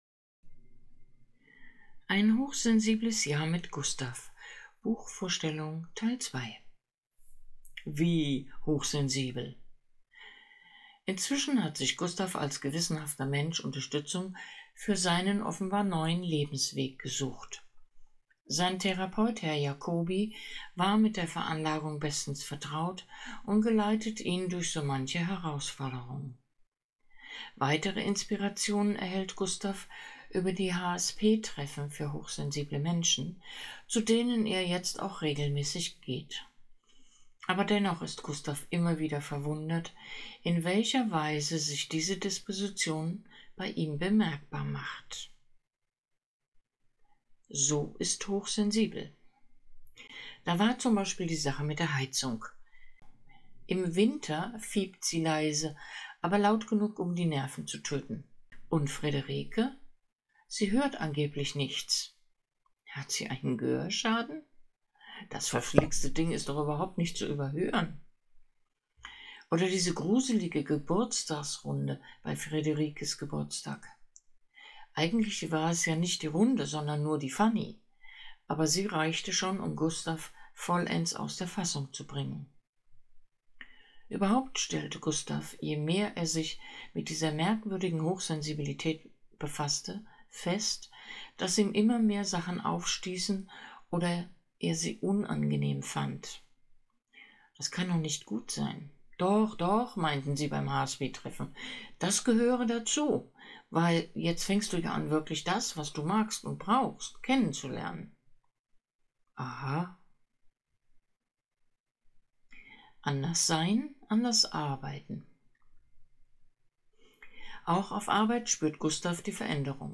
Audio-Buchtipp "Ein hochsensibles Jahr mit Gustav" - Teil 2